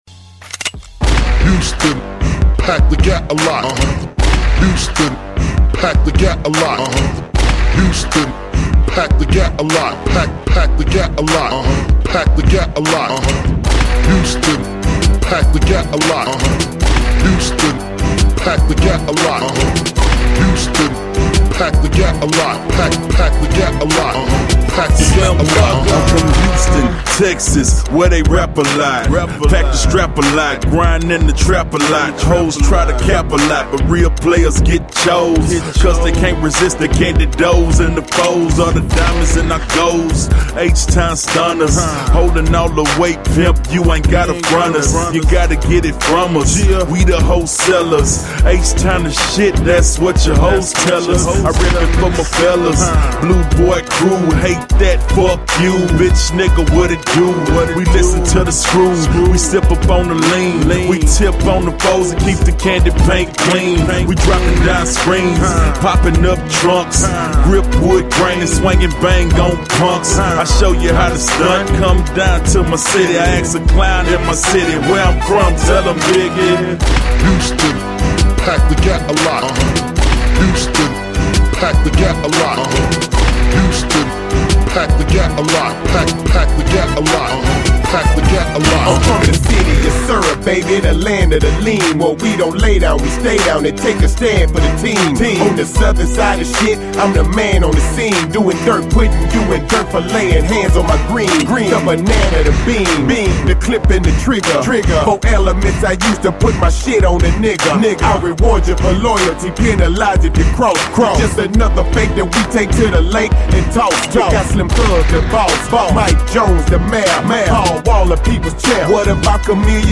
Genre: Hip Hop.